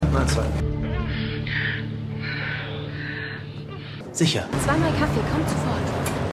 TruthBeTold_3x09_Polizist_MaedchenVideo_Mitarbeiter_Kaffeeverkaeuferin.mp3